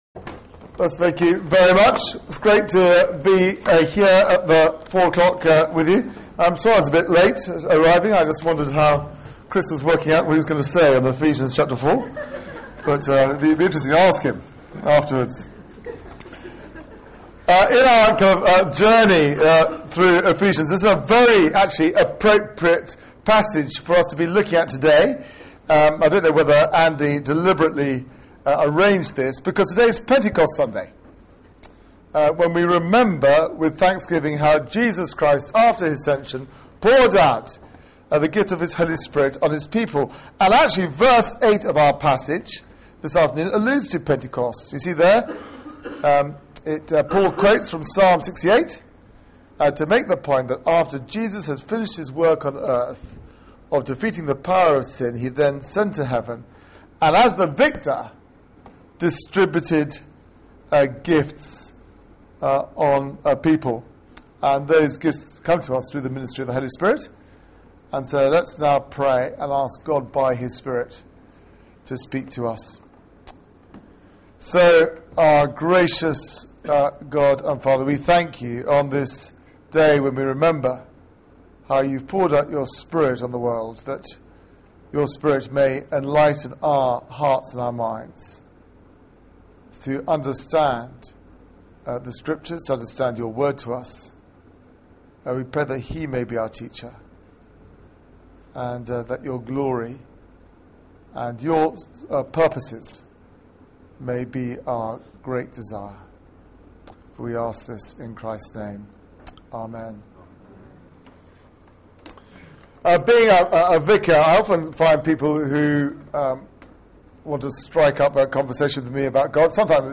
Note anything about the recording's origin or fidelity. Media for 4pm Service on Sun 27th May 2012 Sermon (poor sound quality)